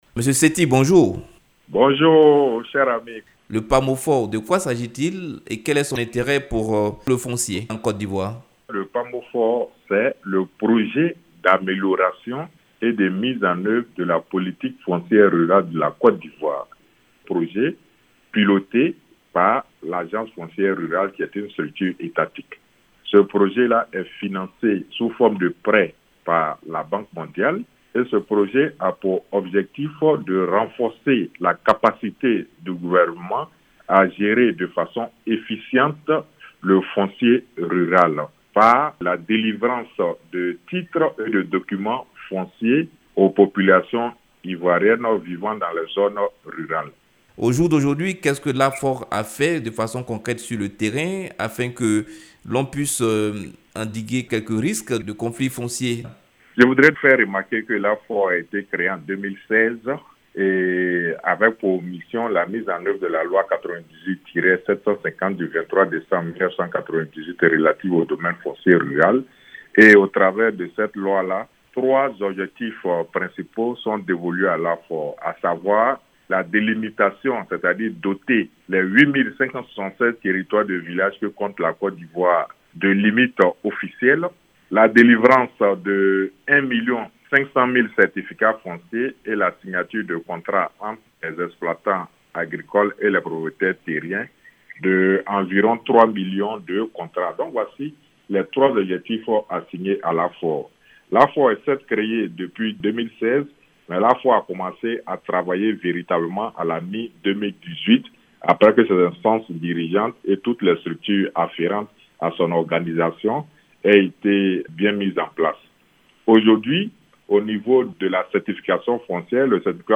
Invité de la Rédaction